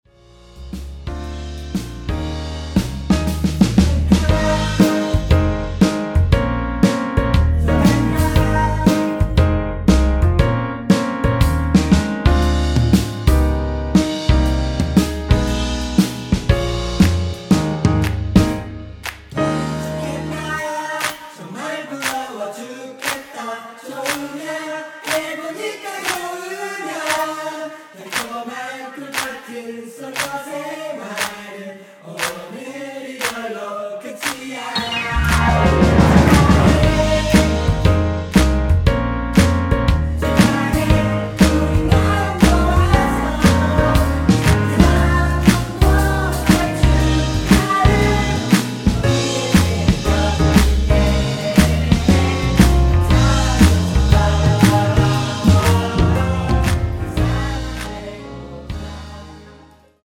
원키에서(+1)올린 코러스 포함된 MR 입니다.(가사와 미리듣기 참조)
Ab
앞부분30초, 뒷부분30초씩 편집해서 올려 드리고 있습니다.